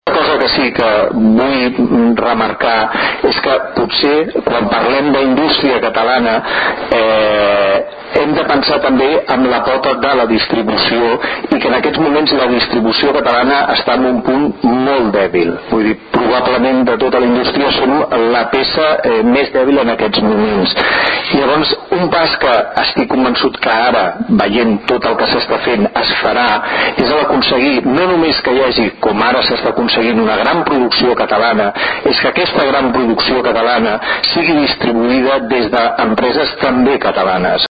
Intervencions durant la roda de premsa
Tall de veu